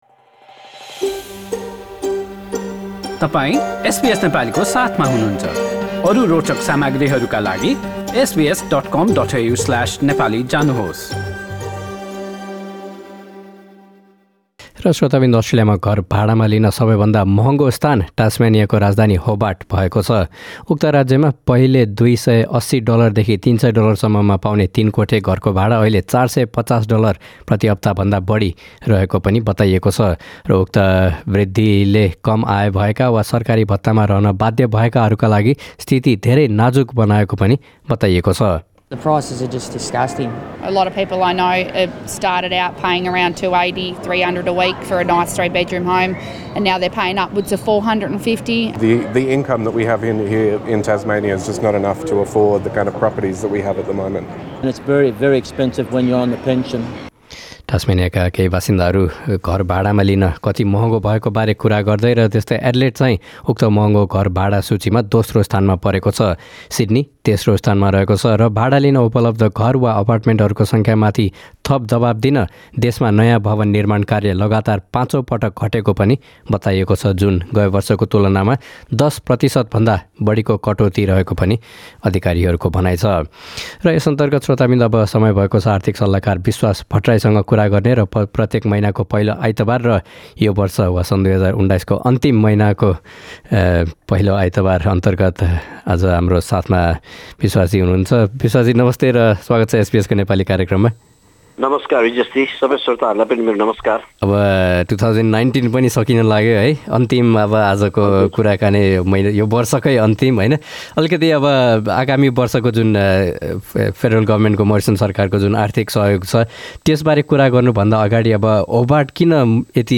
यी लगायतका विषयहरु सम्बन्धि कुराकानी सुन्न माथि रहेको मिडिया प्लेयरमा क्लिक गर्नुहोस्।